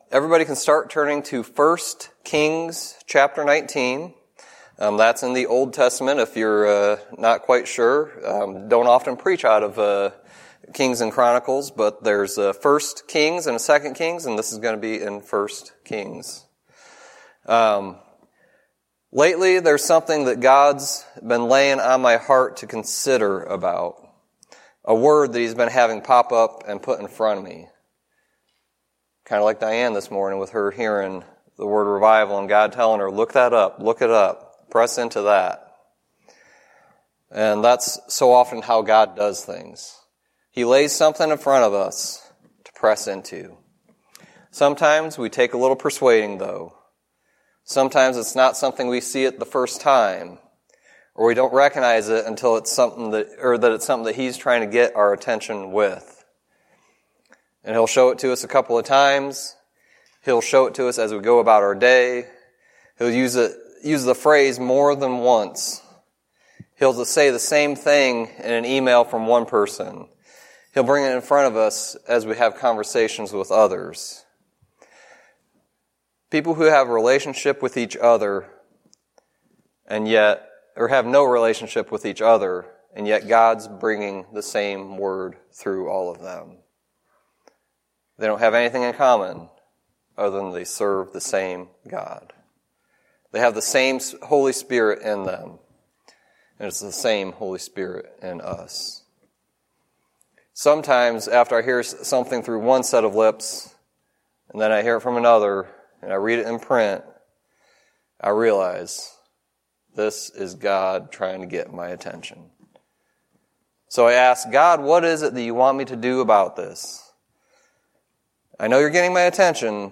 Sermon messages available online.